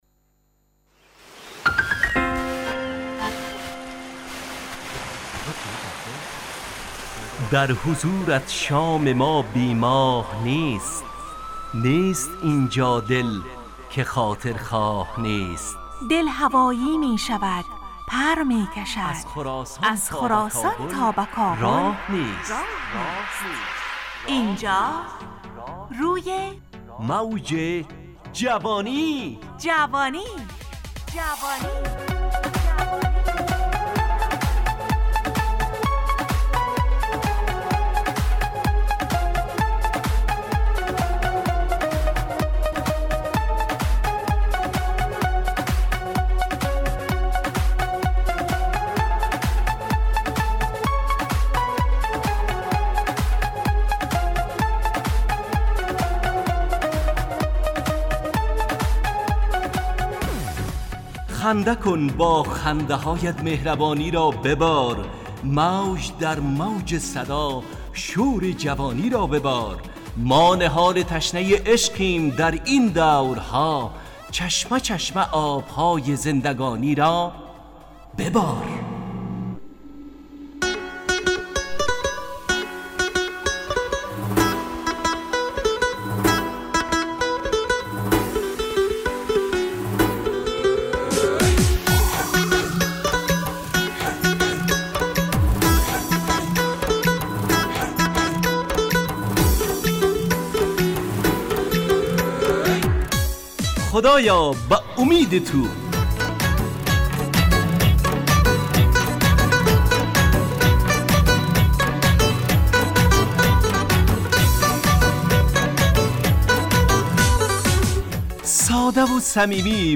همراه با ترانه و موسیقی مدت برنامه 55 دقیقه . بحث محوری این هفته (امید) تهیه کننده